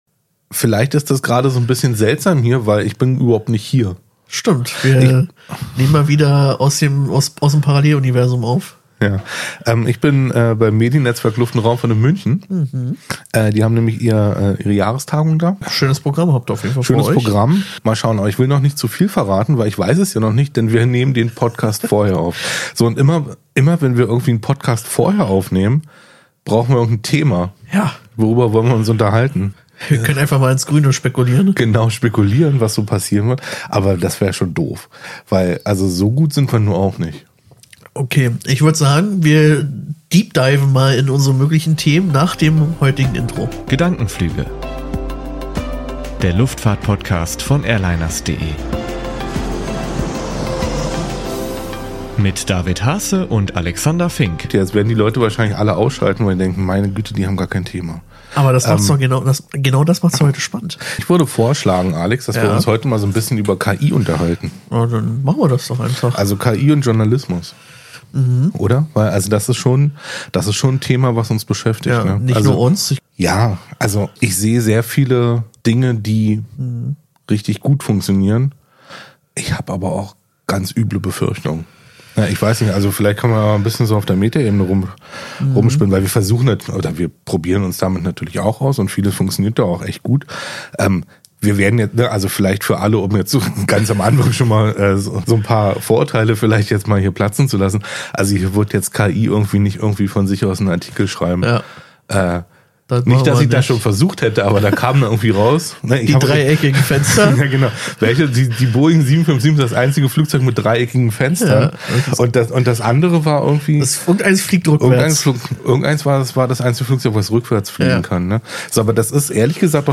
Eine besondere Episode: Komplett unvorbereitet, aber voller spannender Einblicke.